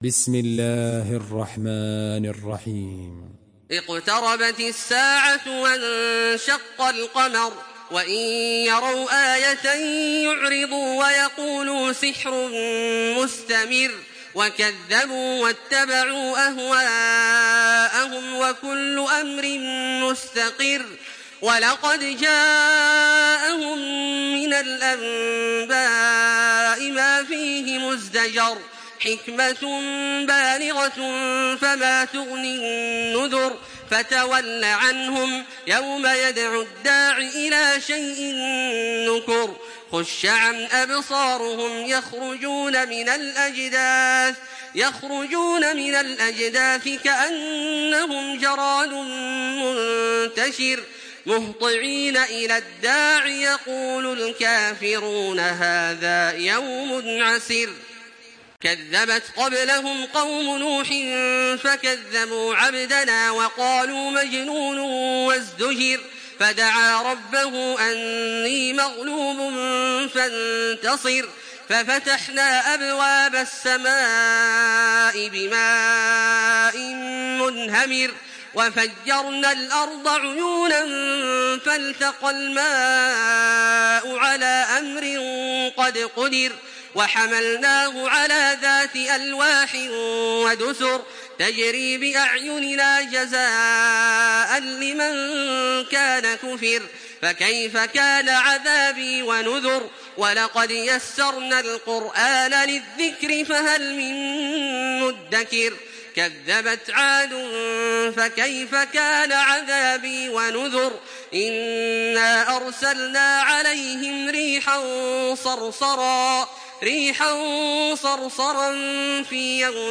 Listen and download the full recitation in MP3 format via direct and fast links in multiple qualities to your mobile phone.
Download Surah Al-Qamar by Makkah Taraweeh 1426
Murattal